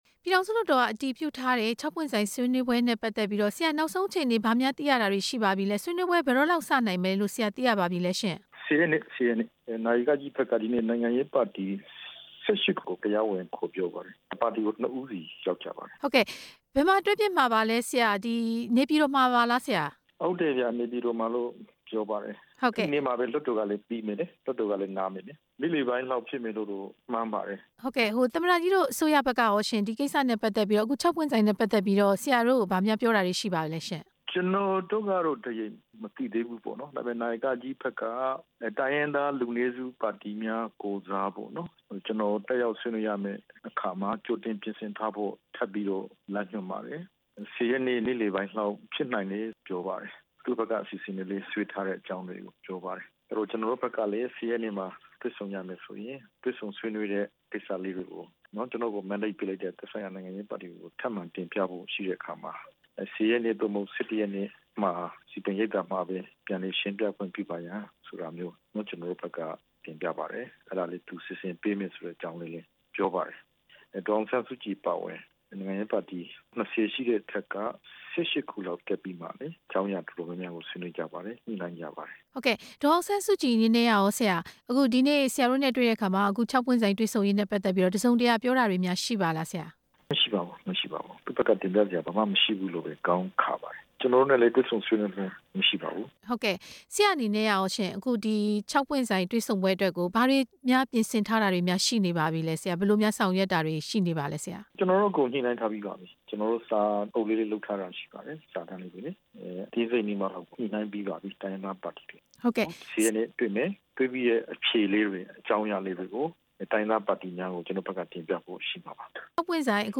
ဒေါက်တာအေးမောင်နဲ့ မေးမြန်းချက်
ဒီ ၆ ပွင့်ဆိုင်တွေ့ဆုံပွဲနဲ့ ပတ်သက်ပြီး အမျိုးသားလွှတ်တော်ကိုယ်စားလှယ် ဒေါက်တာအေးမောင်နဲ့ ဆက်သွယ်မေးမြန်းချက်ကို နားဆင်နိုင်ပါတယ်။